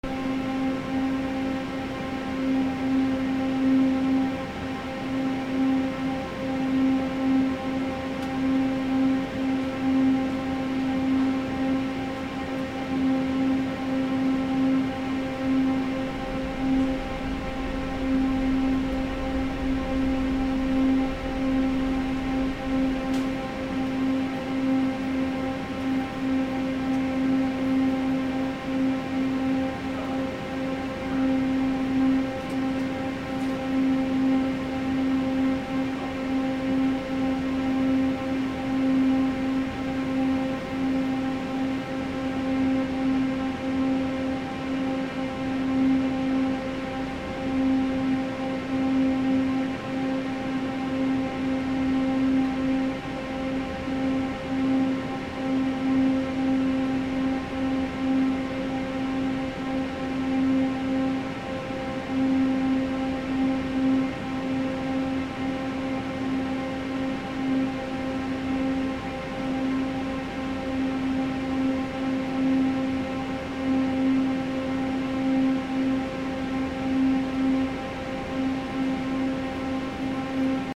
スーパーの冷蔵ケース